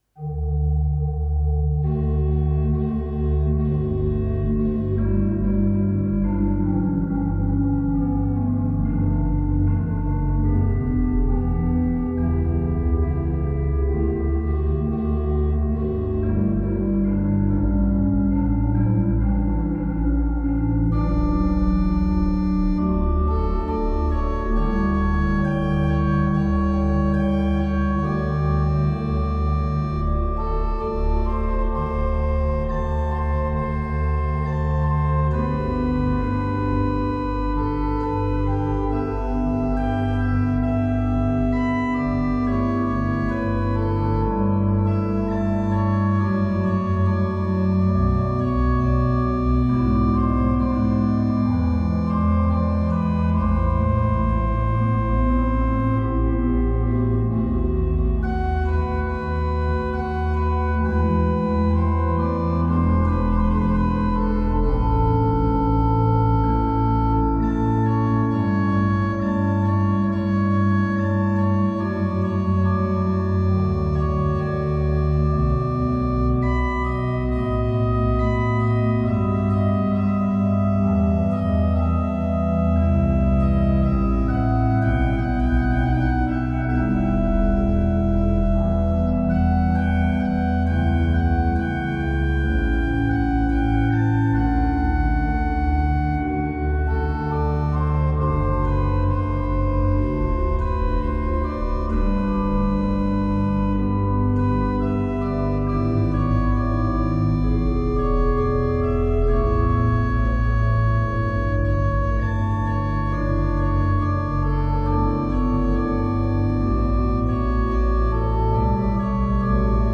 It is a peaceful and contemplative instrumental introduction to the cantata.
• Accompaniment: gamba  Melody: flute
The most important thing is that the atmosphere of stillness remains.